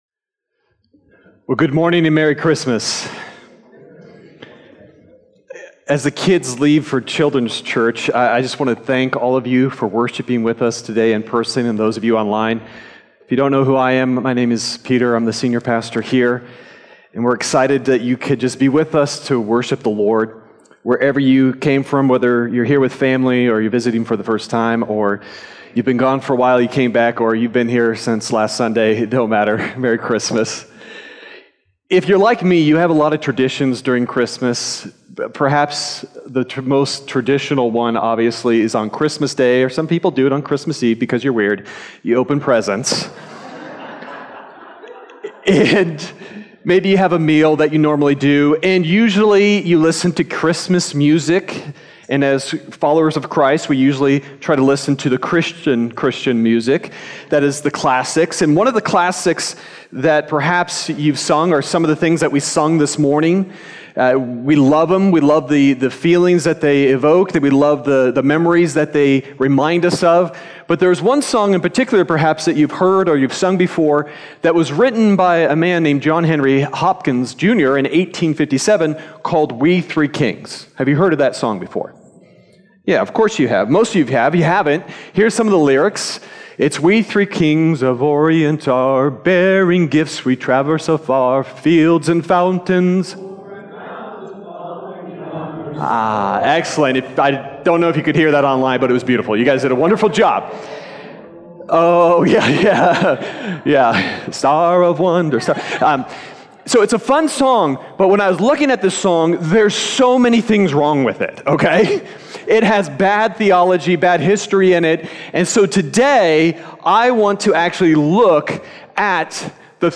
Sermon Detail
December_22nd_Sermon_Audio.mp3